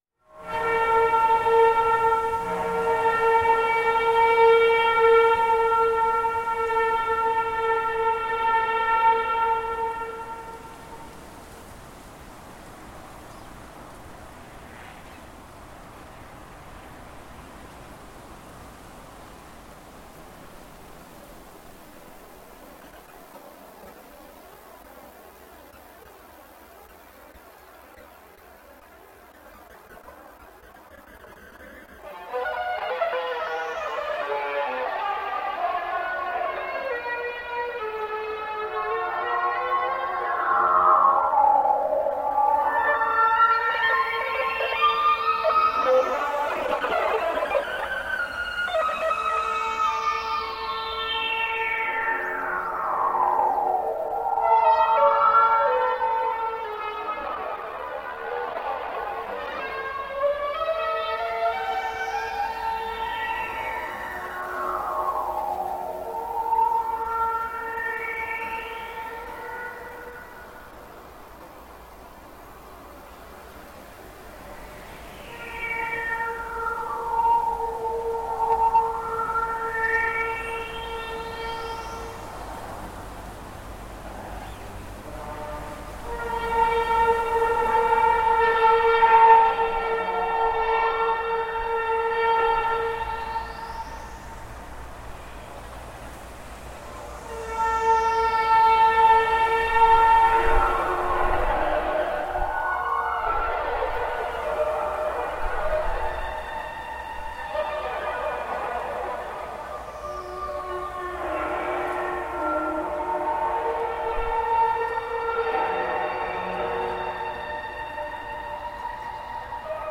The sound was too consistent for that…
The source appeared to be a bright yellow machine that was working there, twisting and turning within the barn.
The "re-imagining” starts with the raw sound and then comprises purely of that layered in various guises. Nothing external (loops, samples etc) has been added.